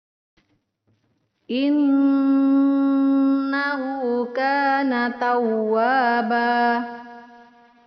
Panjangnya adalah 2 harokat.